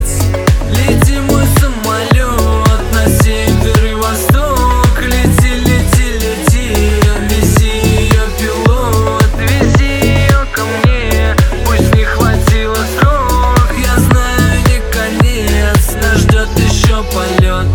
• Качество: 320, Stereo
поп
мужской вокал